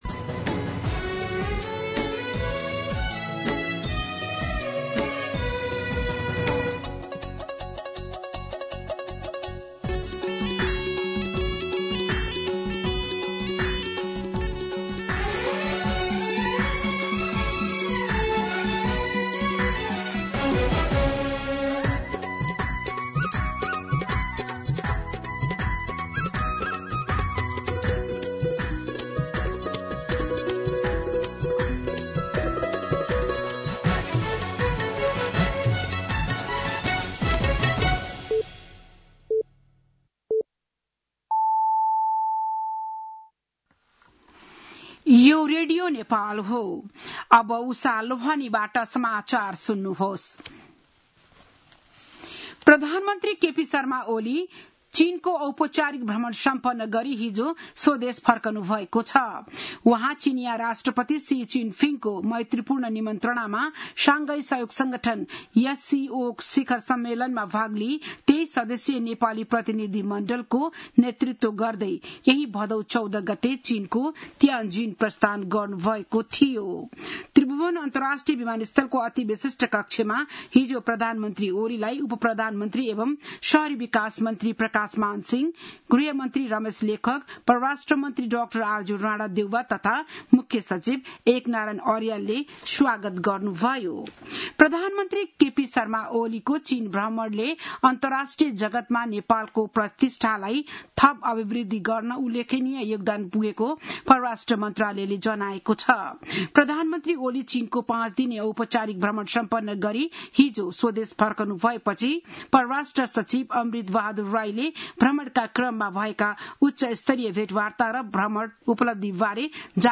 बिहान ११ बजेको नेपाली समाचार : १९ भदौ , २०८२